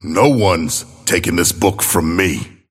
Abrams voice line - No one's taking this book from me.